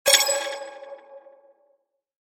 دانلود آهنگ اعلان خطر 6 از افکت صوتی اشیاء
جلوه های صوتی